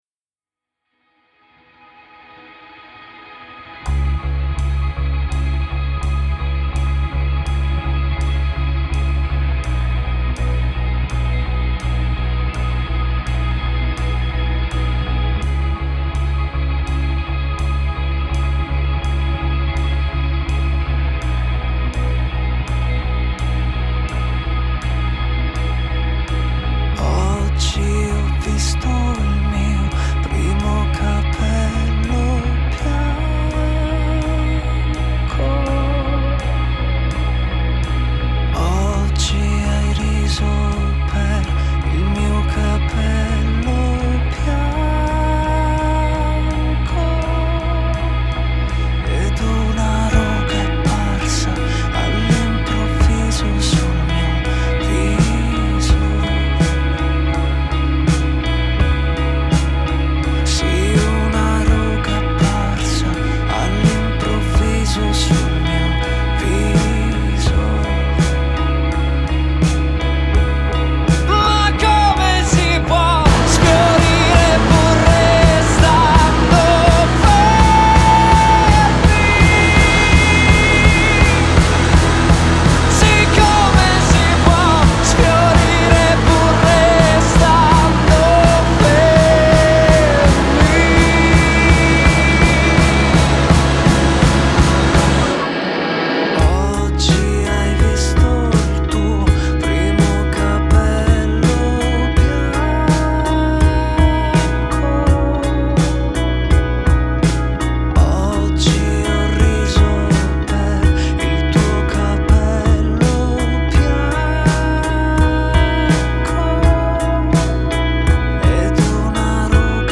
Genre: Pop Rock, Indie, Alternative